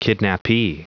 Prononciation du mot kidnapee en anglais (fichier audio)
Prononciation du mot : kidnapee